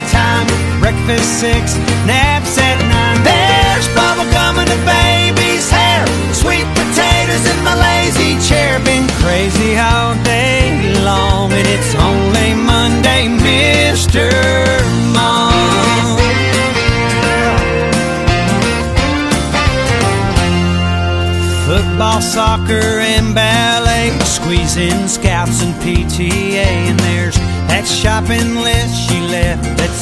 pocorn noises on aux